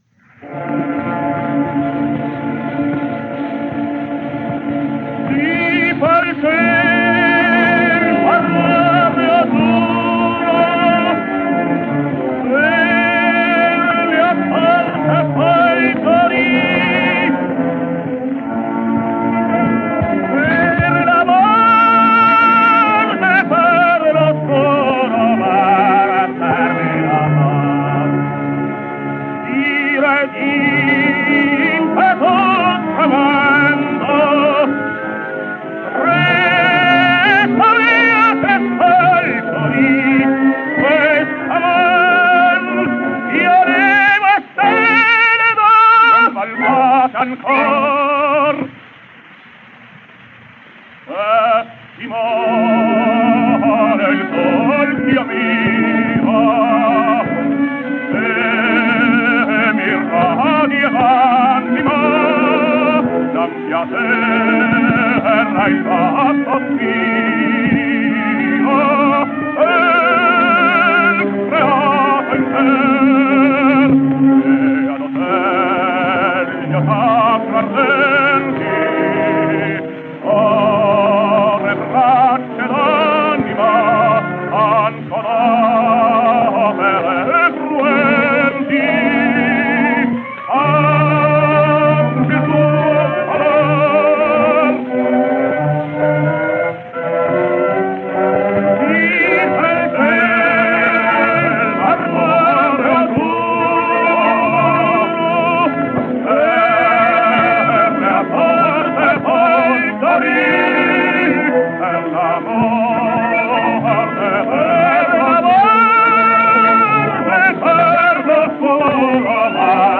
Giovanni Zenatello | Italian Tenor | 1876 - 1949 | Tenor History